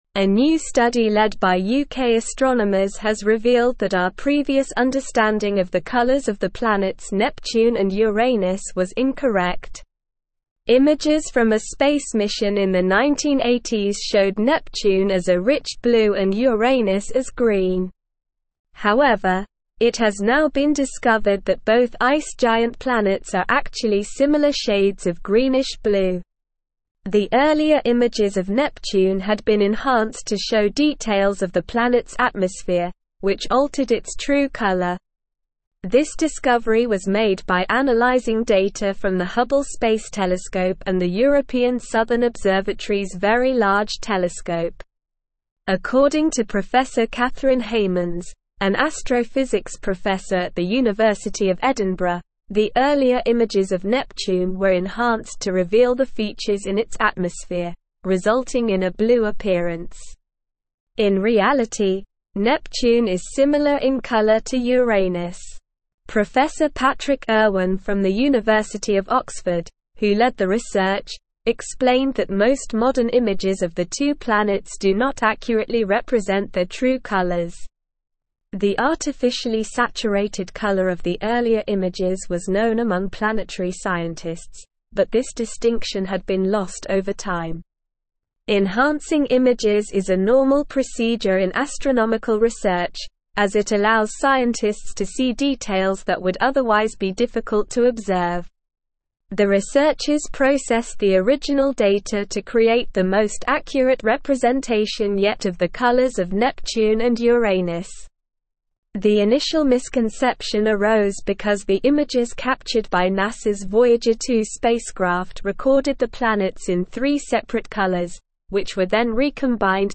Slow
English-Newsroom-Advanced-SLOW-Reading-True-Colors-of-Neptune-and-Uranus-Revealed.mp3